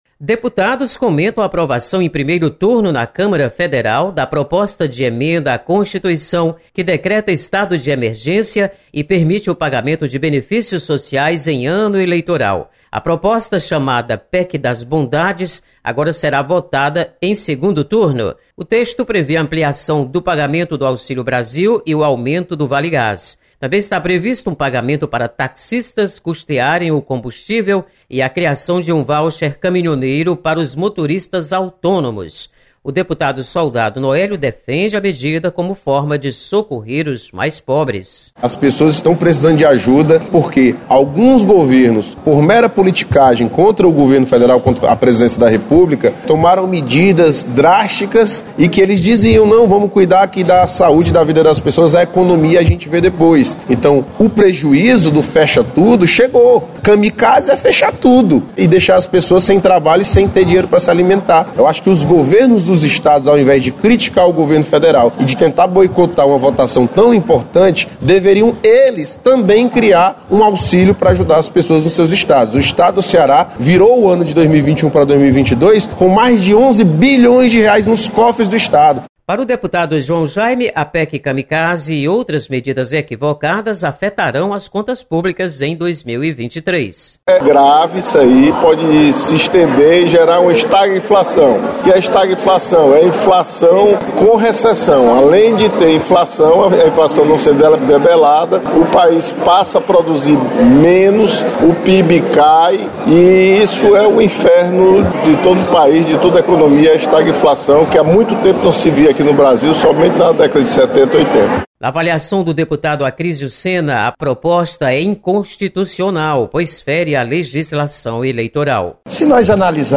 Deputados comentam a aprovação em primeiro turno da PEC das Bondades em Brasília.